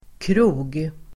Ladda ner uttalet
Uttal: [kro:g]